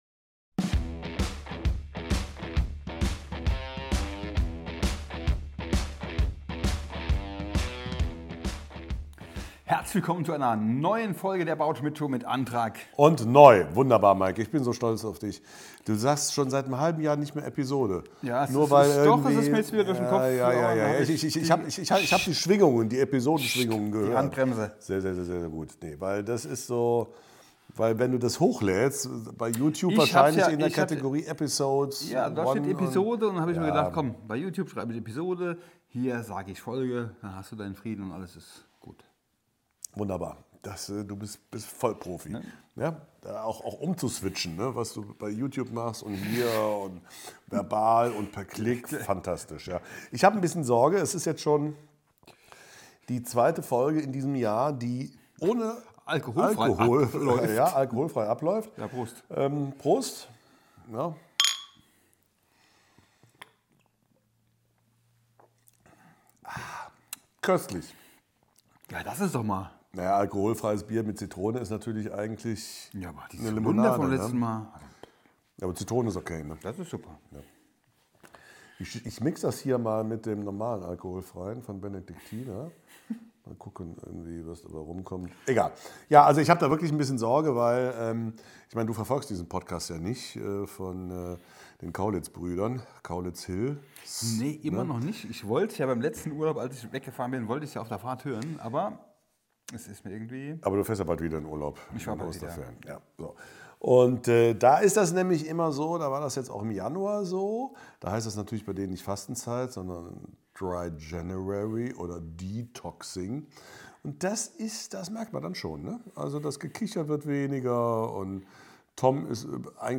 In einem Feuerwerk der Radio-Comedy werden alle Stilformen des guten alte Radios durch den Kakao gezogen: Das schmalzige Nacht-Radio, christliche Gedanken, ambitionierte Hörspiele, Radio-Werbung, Verkehrshinweise, Kulturprogramm und natürlich auch: Radio Comedy! Vor 25 Jahren wurde dieses Juwel in der Nacht kurz vor zwei Uhr morgens gesendet, heute gibt es die besten Ausschnitte in eurem Lieblings-Podcast.